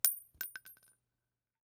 CassingDrop 05.wav